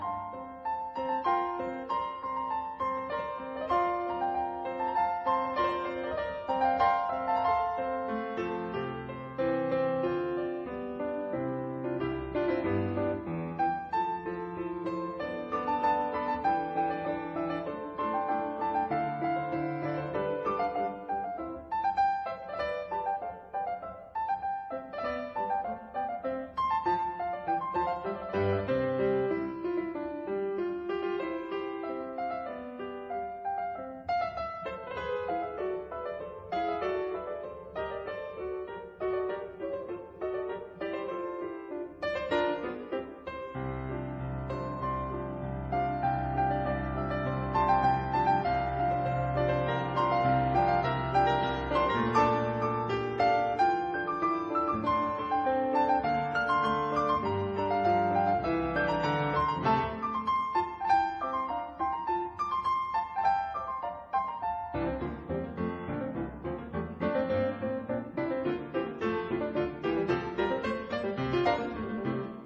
反而多了些古典的味道。